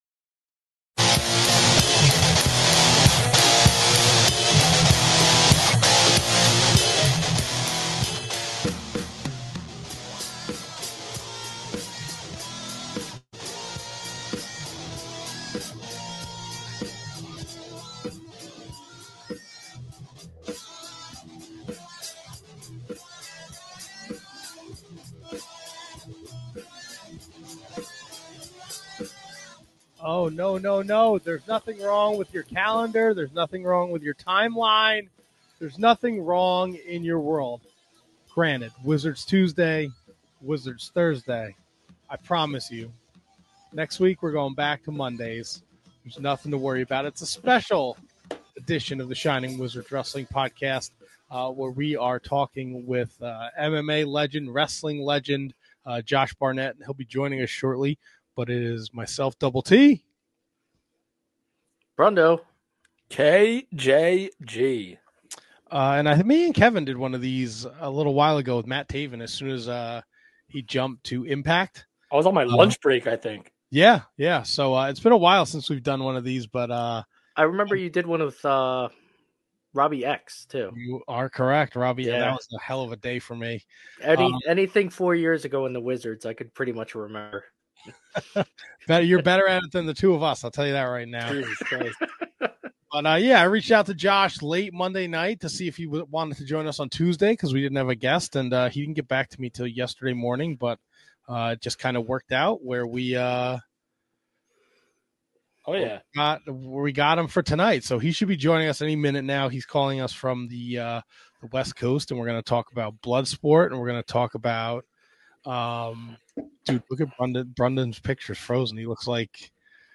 The Shining Wizards Special Interview with MMA & Wrestling Legend Josh Barnett